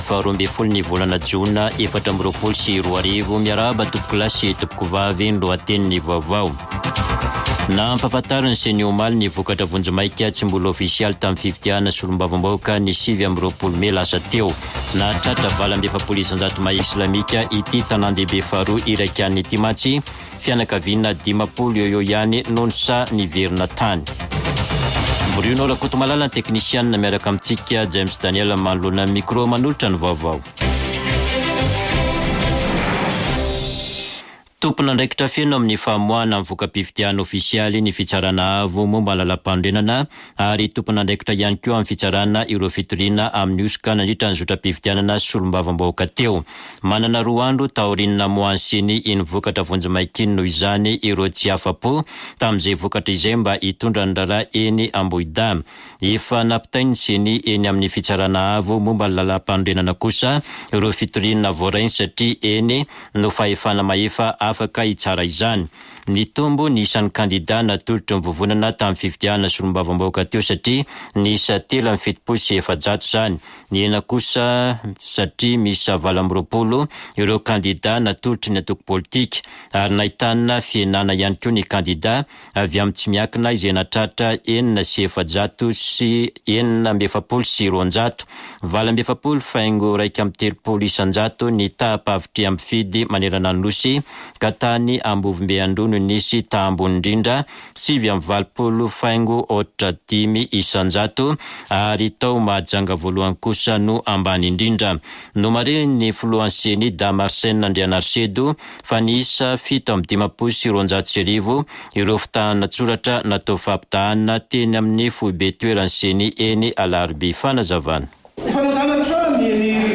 [Vaovao maraina] Alarobia 12 jona 2024